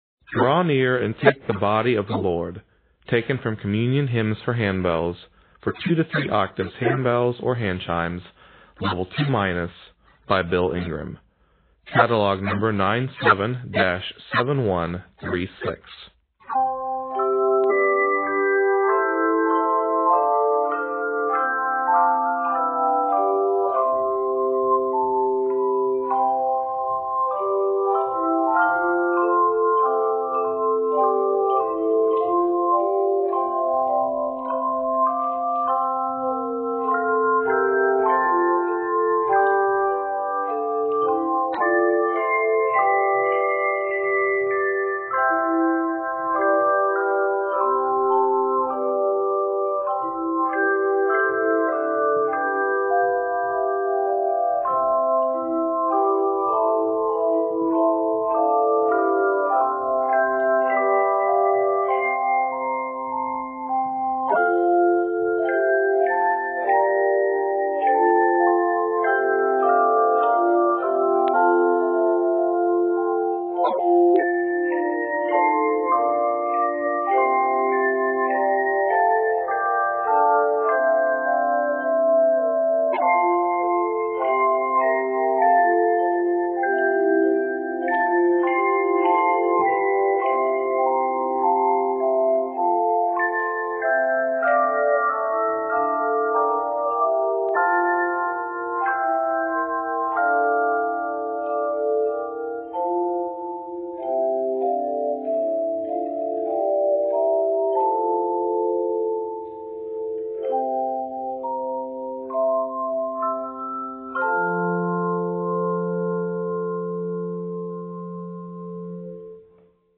Octaves: 2-3